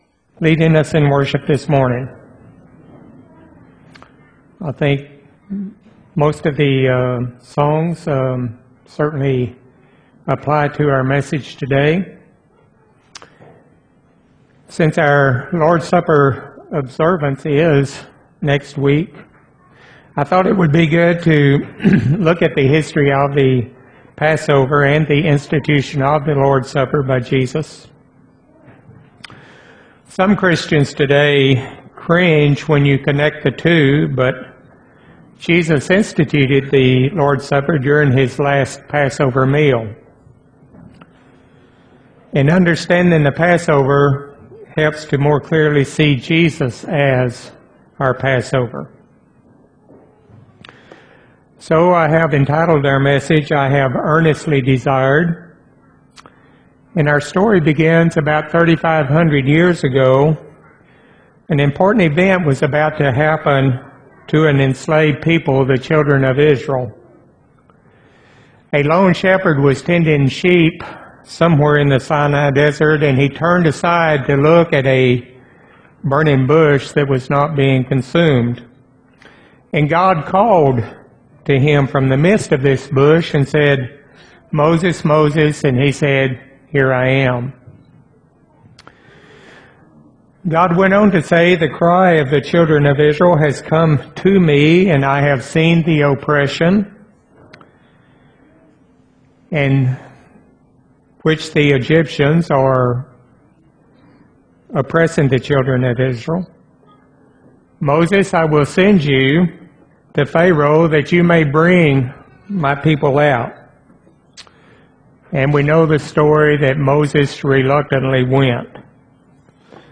3-31-12 sermon